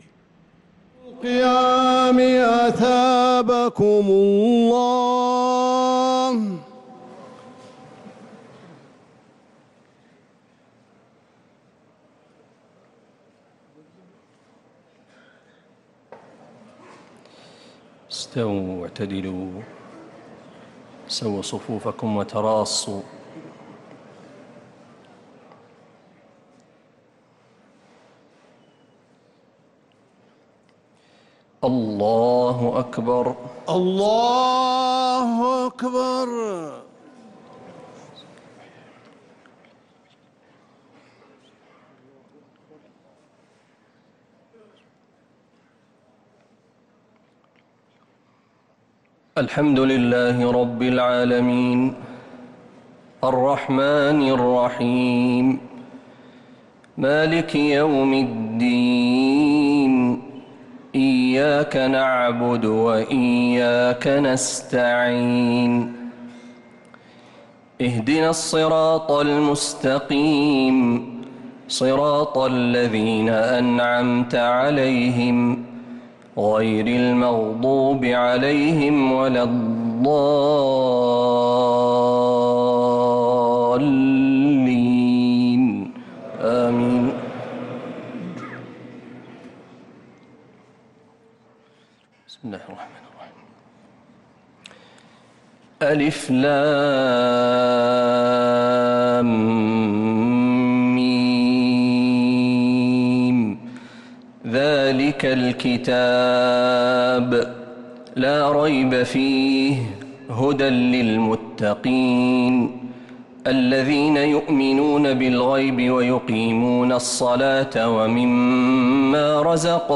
صلاة التراويح ليلة 1 رمضان 1445 للقارئ محمد برهجي - الثلاث التسليمات الأولى صلاة التراويح